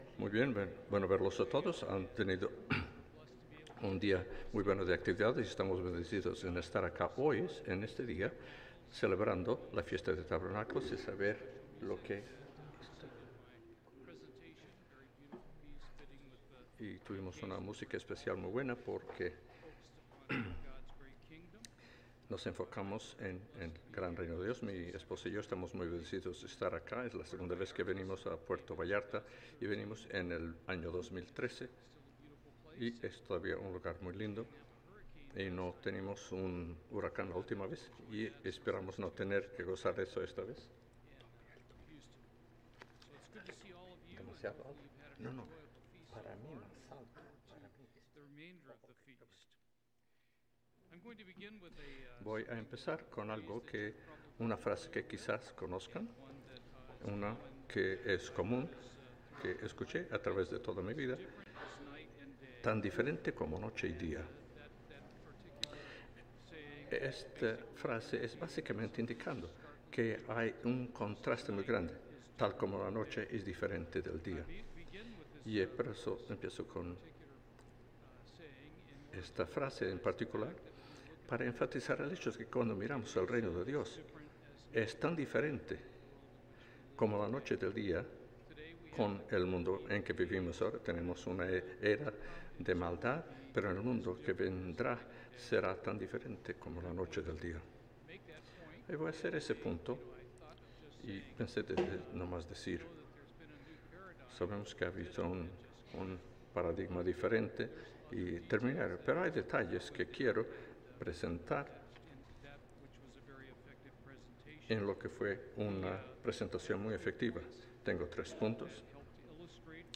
Given in Puerto Vallarta, Mexico